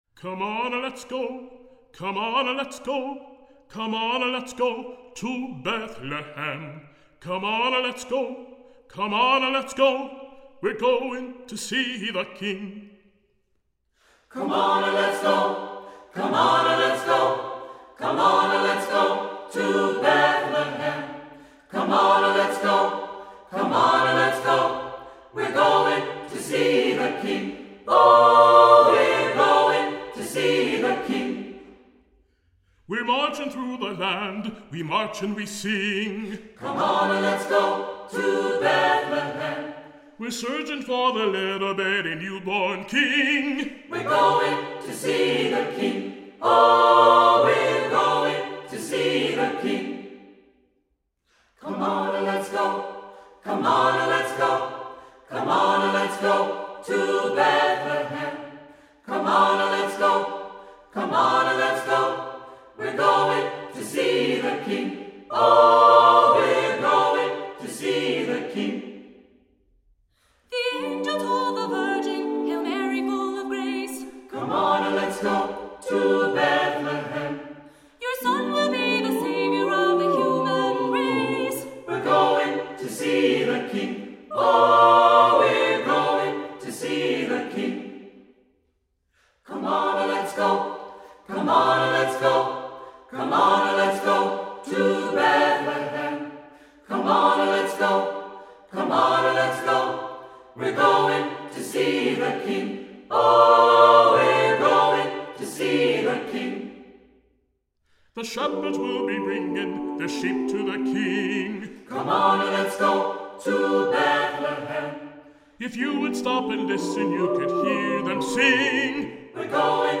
Voicing: Assembly,Cantor,SATB,Soloist or Soloists